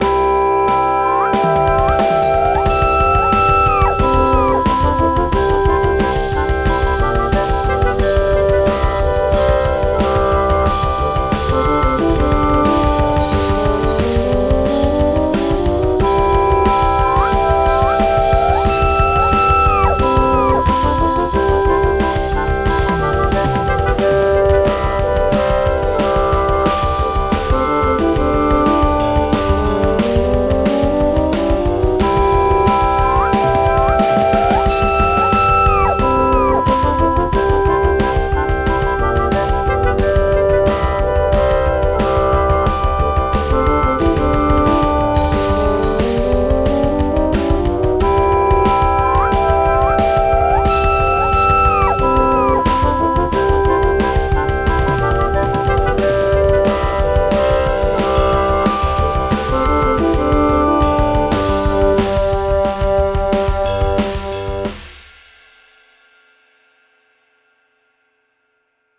I think everything sounds better with drums . . . :)
Remix Version 2
the beginning of a wind quintet in a classic style